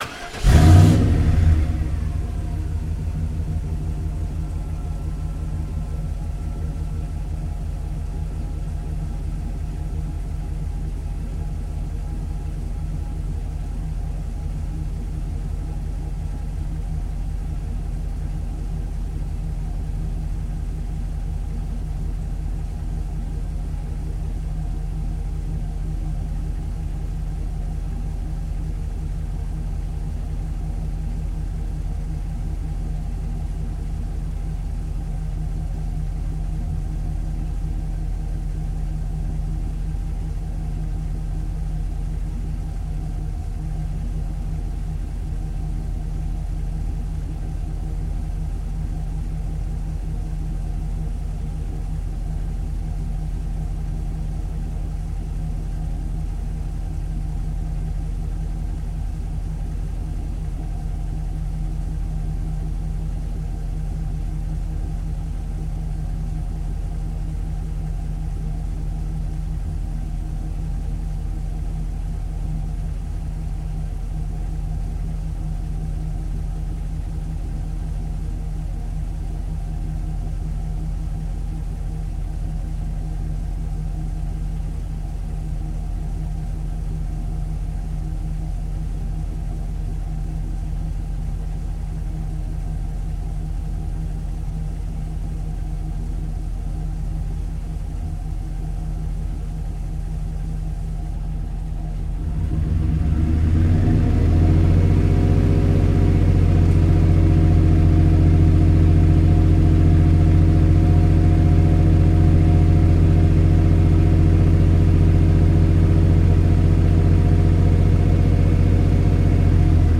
Звук: фургон заводится, работает на холостых оборотах, затем плавно трогается